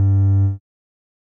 bass
添加三个简单乐器采样包并加载（之后用于替换部分音效）